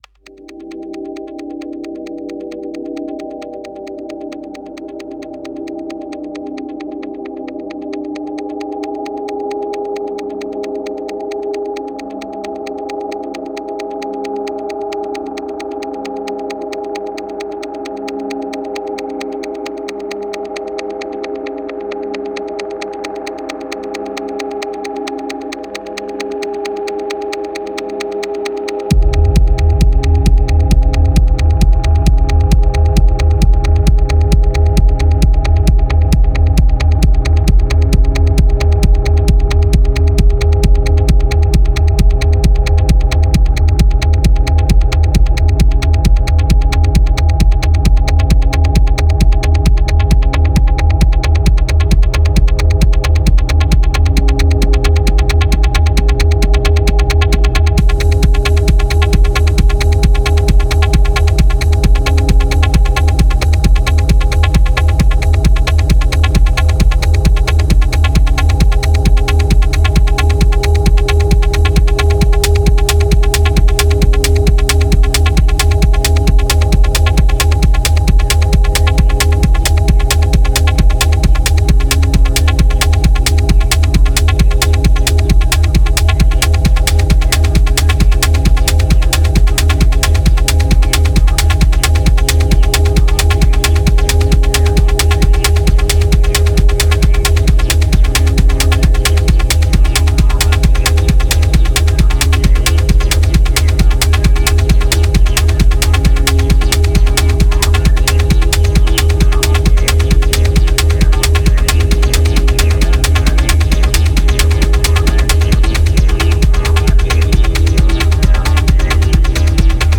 Genre: Tech House, Techno, Minimal.